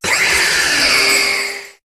Cri de Lunala dans Pokémon HOME.